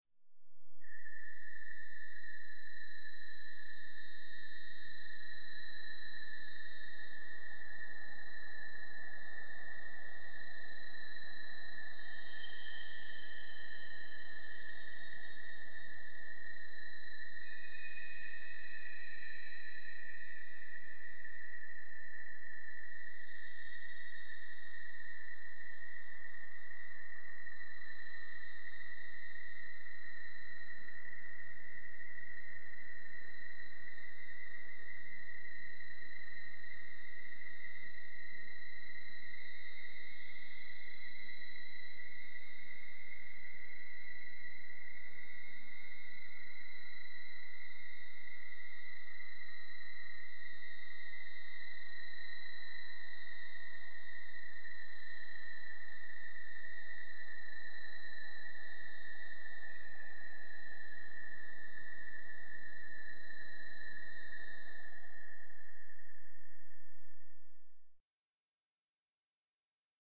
Синтезатор "Kurzweil", 1996, 2008 гг.